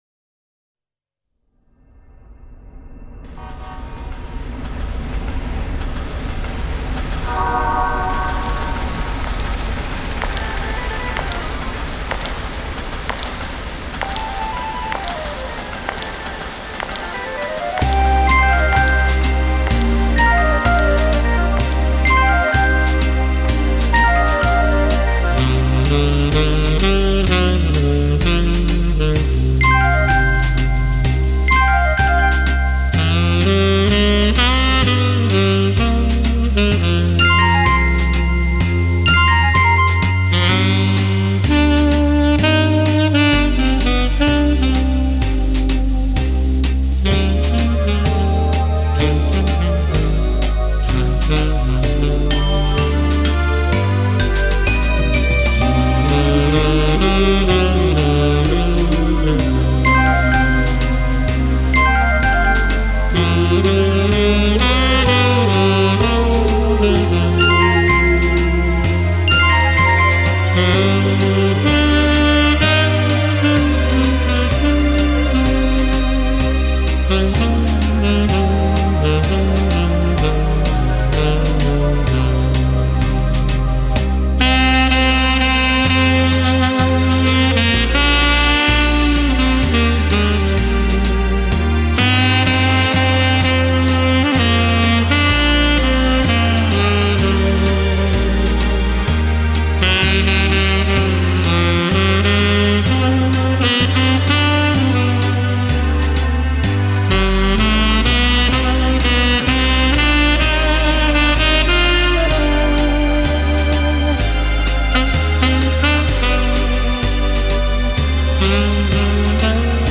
Но запись достаточно чистая.